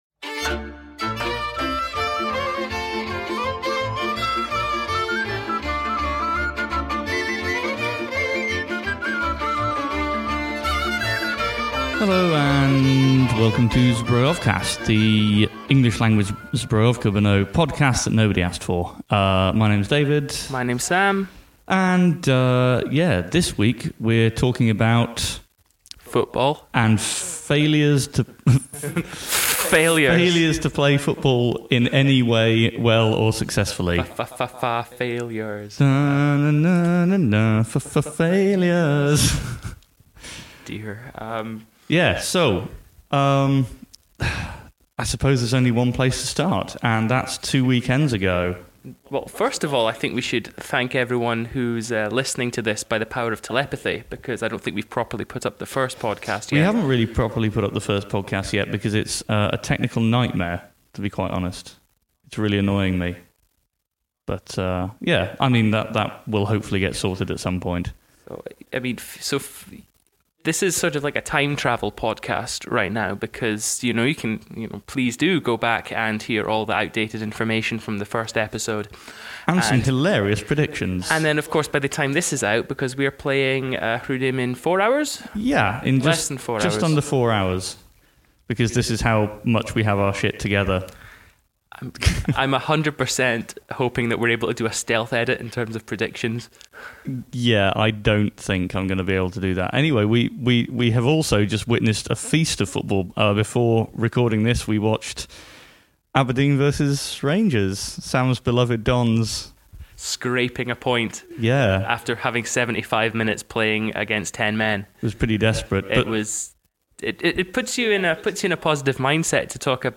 Episode 2: In which mic complacency leads to echo
In a badly recorded episode (sorry, we'll do better next time!), we look at Zbrojovka's first two games, at home to Jihlava and away to (insert famous duo) Vlašim.